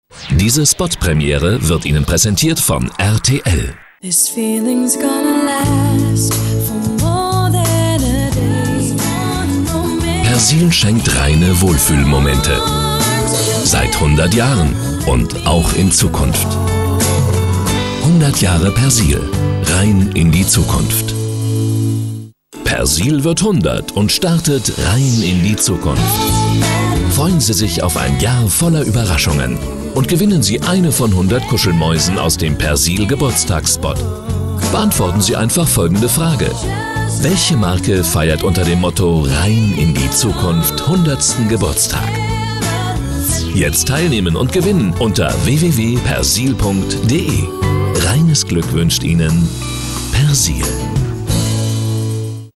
Sprechprobe: Industrie (Muttersprache):
German voice over artist with more than 30 years of experience.